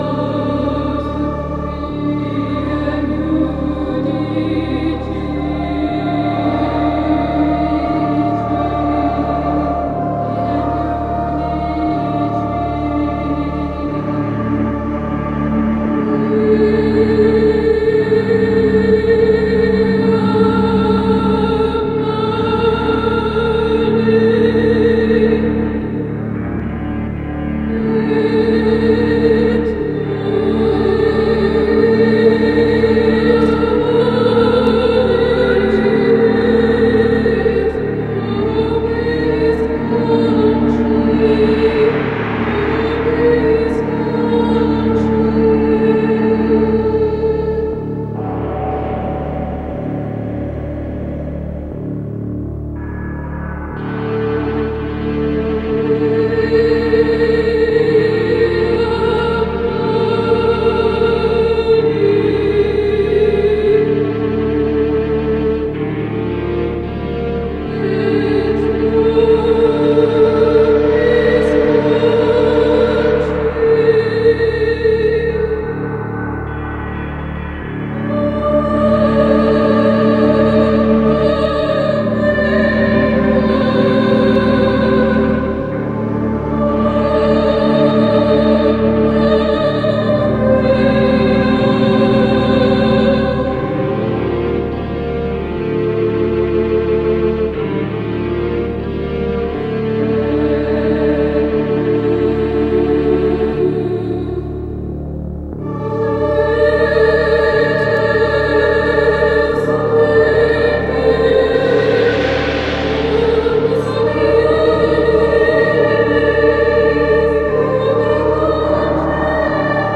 sintetizador / sampler / workstation Fairlight CMI